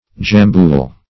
Jambool \Jam"bool\